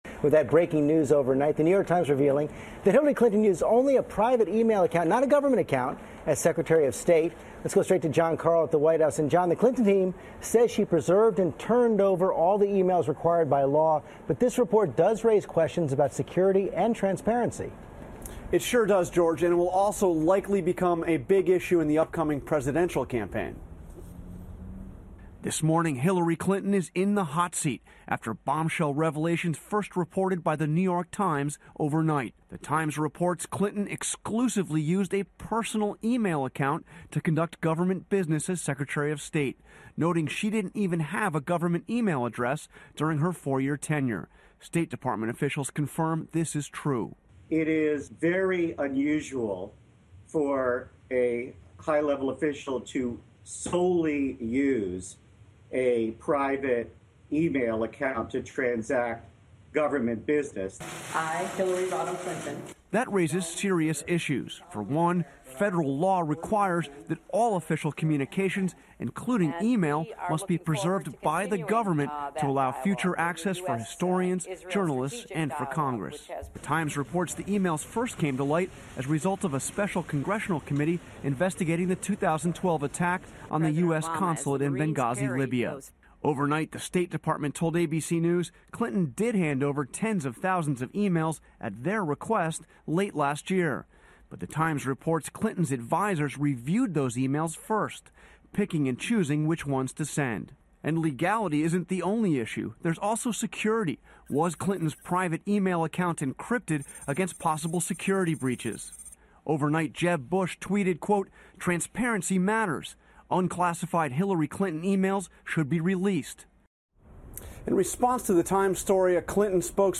访谈录 希拉里用个人邮箱处理公务遭批 被指视安全于无物 听力文件下载—在线英语听力室